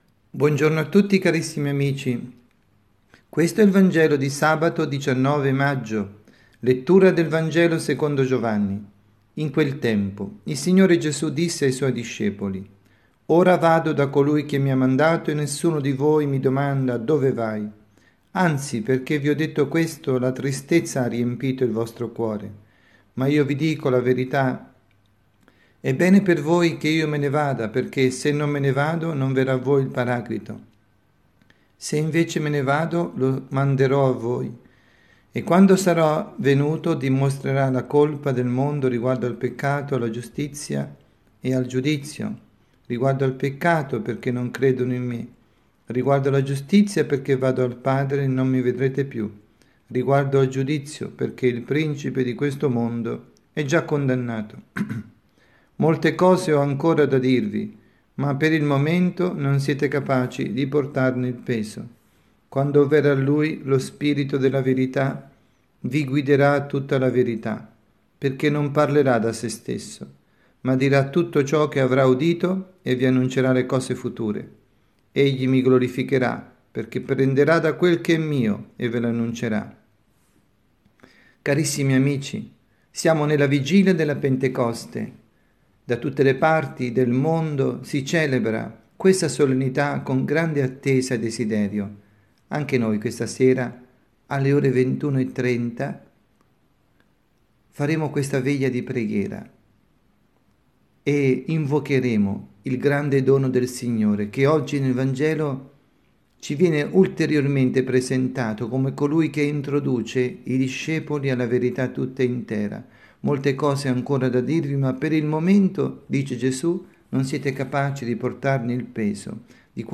Veglia di Pentecoste
dalla Parrocchia S. Rita – Milano